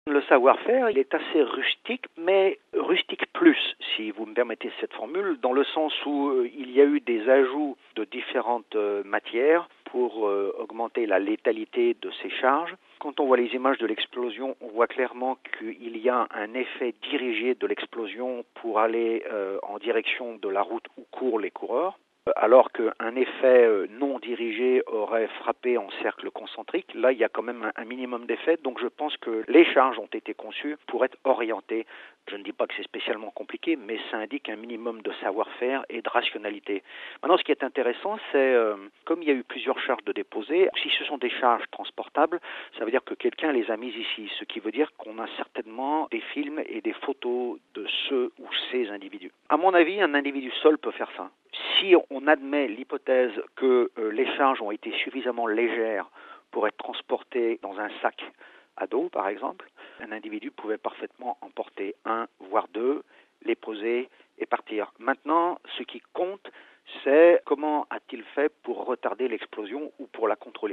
Il est interrogé par